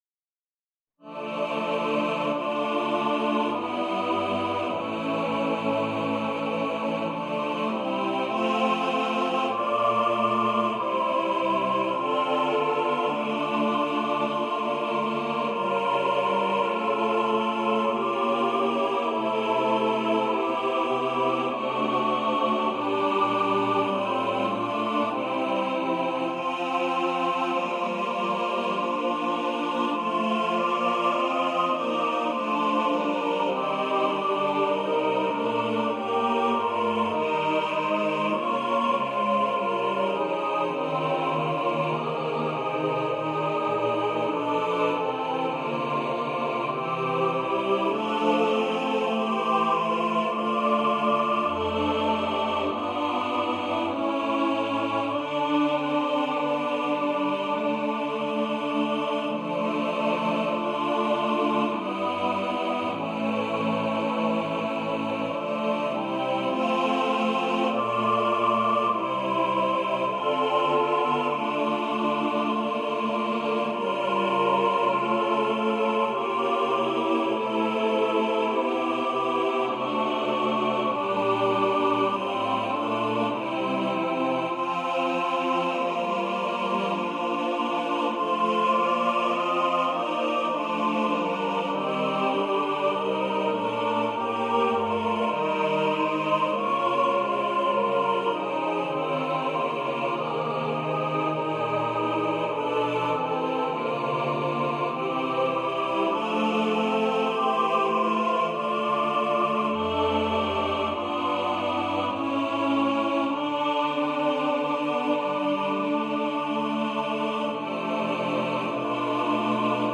Title: Bethlehem Down Composer: Peter Warlock Lyricist: Bruce Bluntcreate page Number of voices: 4vv Voicing: SATB Genre: Sacred, Carol
Language: English Instruments: A cappella